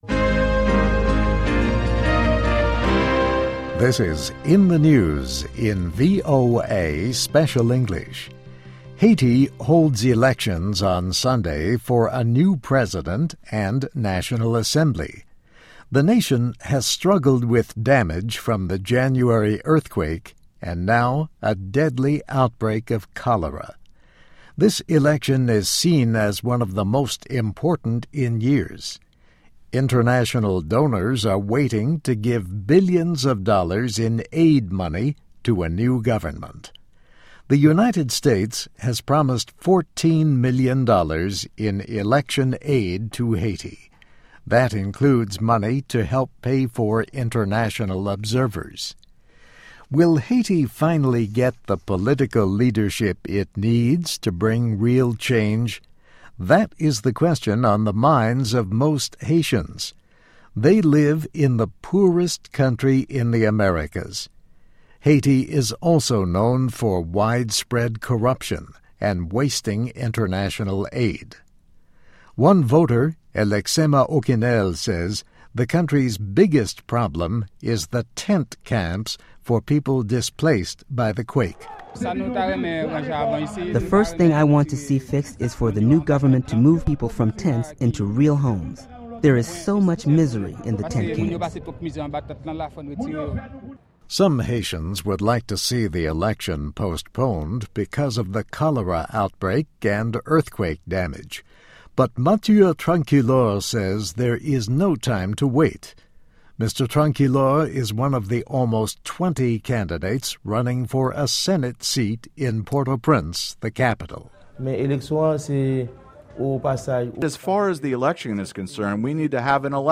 Haitians Face Major Election, but How Many Will Vote Is Unclear (VOA Special English 2010-11-26)